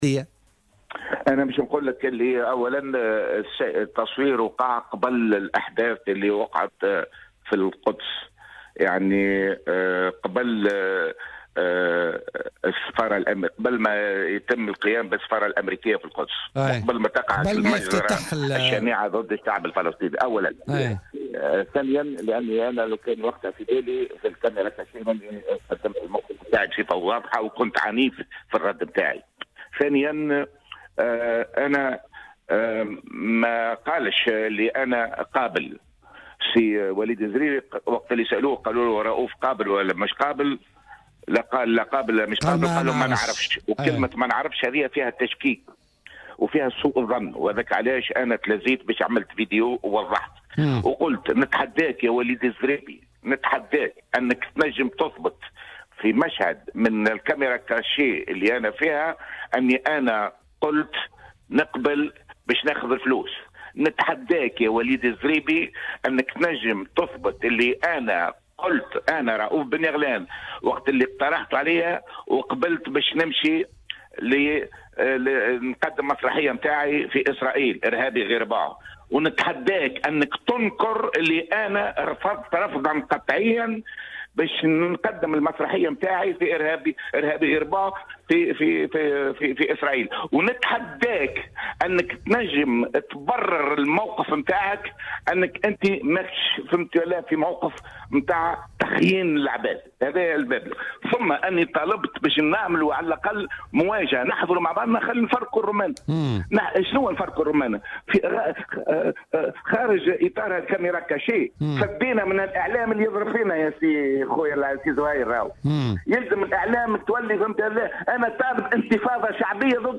وأوضح بن يغلان ضيف "بوليتكا" اليوم الأربعاء، أنه يفتخر بموقفه الذي أبداه خلال هذا البرنامج والشراسة التي أظهرها في مواجهة العرض الإسرائيلي، مطالبا بالاطلاع على النسخة التي سيتم بثها حتى لا يتم التلاعب بها وتشويهها.